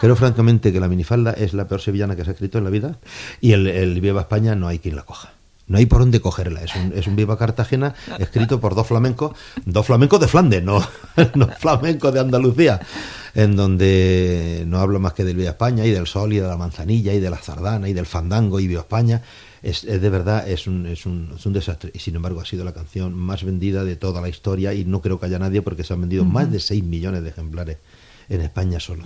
El cantant Manolo Escobar fa autocrítica d'alguna de les seves cançons
Fragment extret del programa "Audios para recordar" de Radio 5 emès el 8 de febrer del 2013.